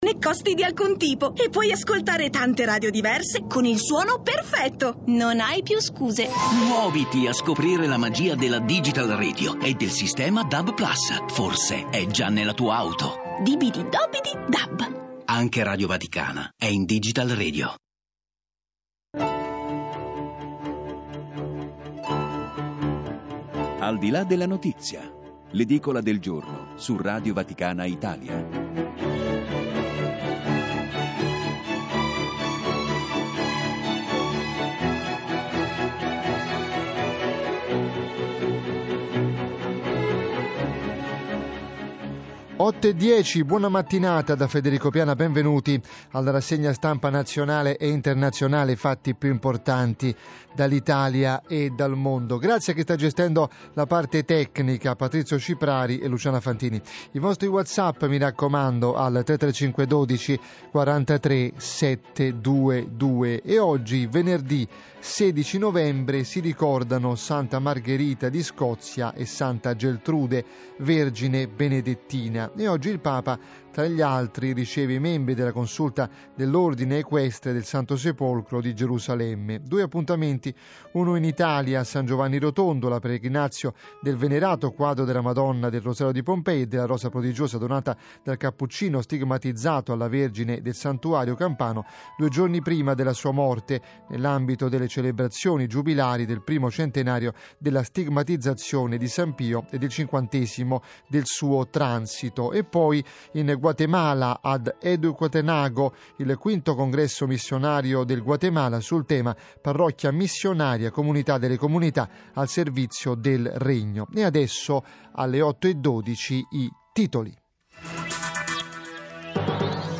Radio Vaticana - Intervista